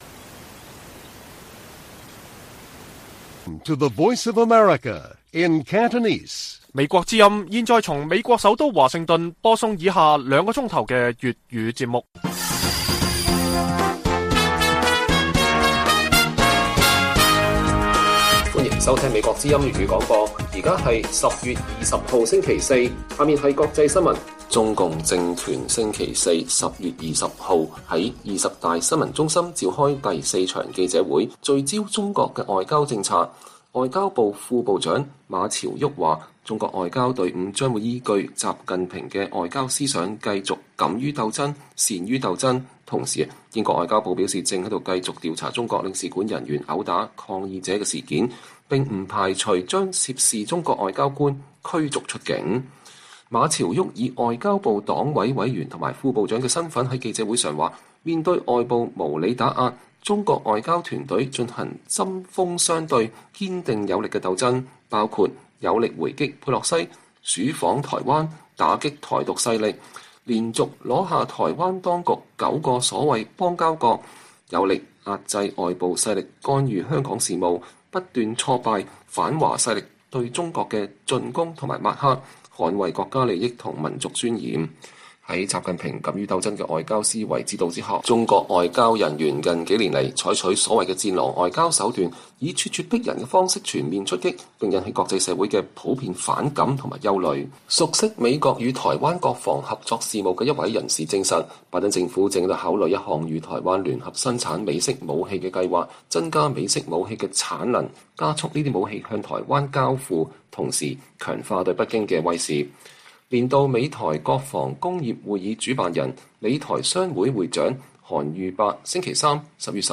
粵語新聞 晚上9-10點 : 中國領事館毆打案受傷者：襲擊“行為野蠻” 擔心家人安全但會繼續參與示威